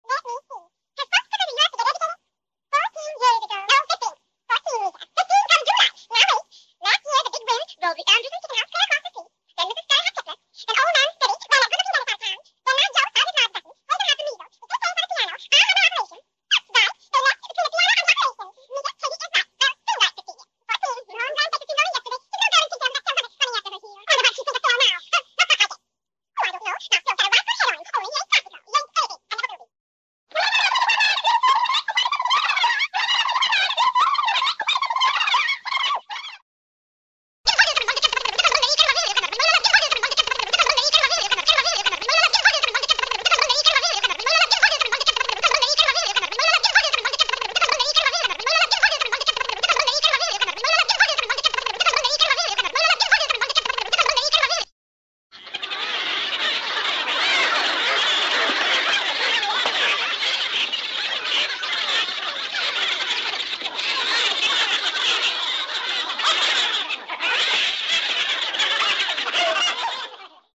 Звуки мультяшного голоса
Звук мультяшного разговора маленькой девочки